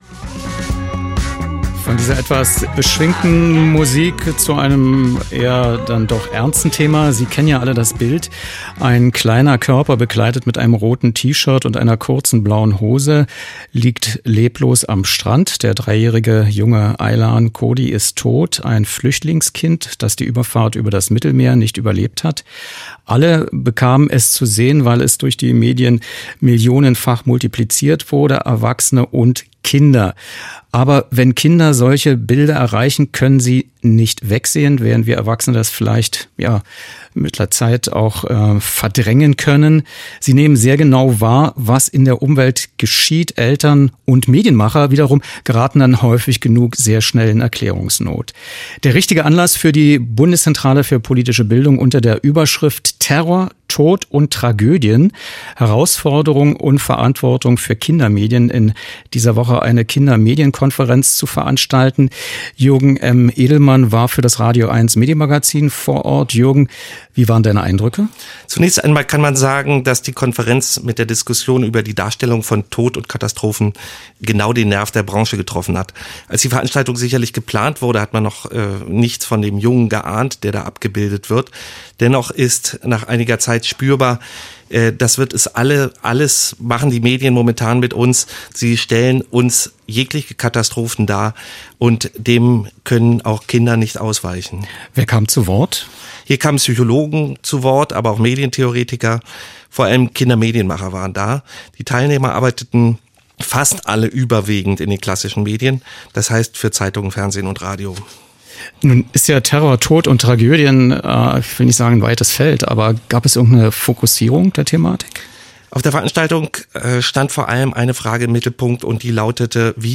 Was: Studiogespräch über die Kindermedienkonferenz
Wo: radioeins-Sendestudio, Medienstadt Babelsberg